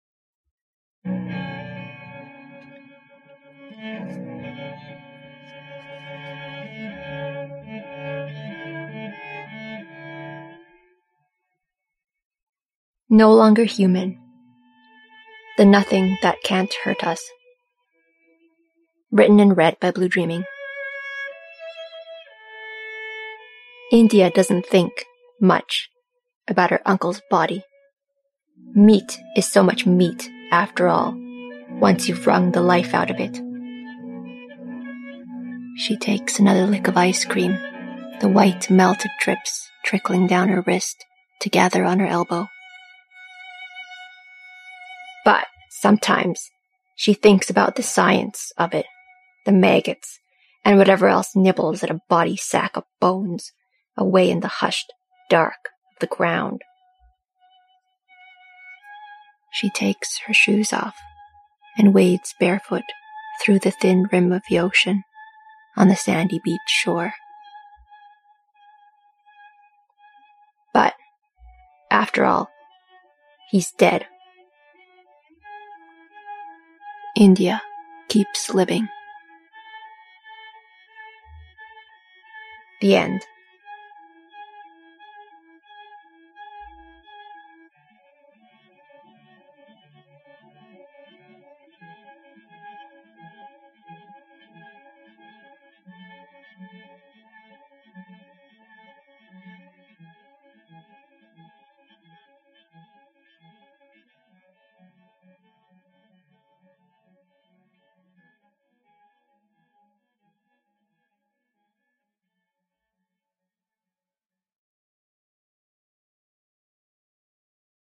Podficcer's notes: A no-music version, and two versions with music recorded on different mics.
recorded on zoom h1n:
Music is the opening of The way they wept from Only in the Dark by Annie Blythe, Brendon Randall-Myers.